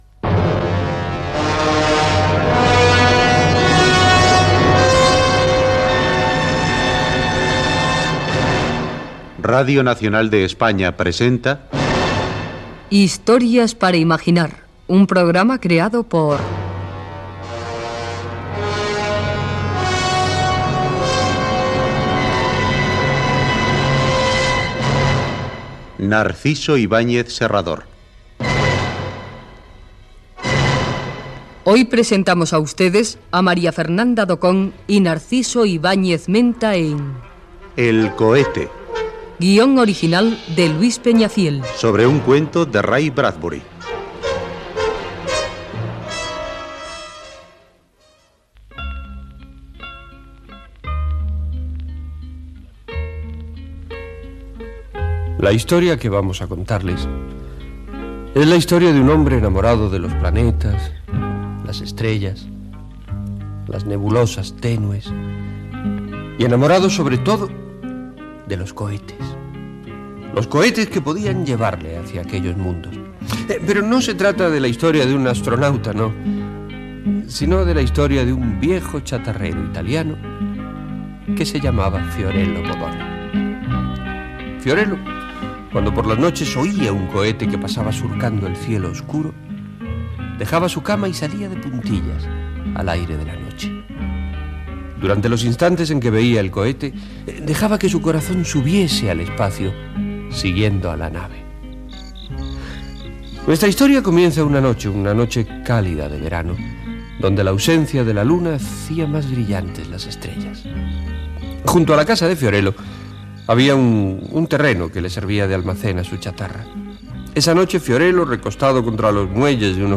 Careta del programa. Espai "El cohete", adaptant una història de Ray Bradbury. Chicho Ibáñez Serrador presenta l'obra i la situció dels personatges. Diàleg de la primera escena de l'obra
Ficció